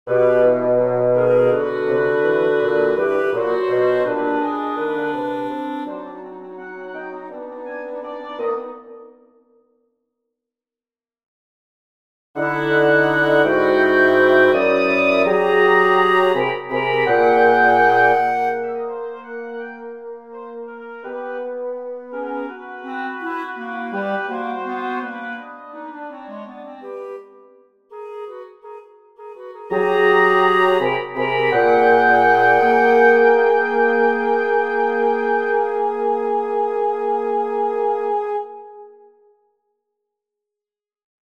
Sketch Saxophone Quartet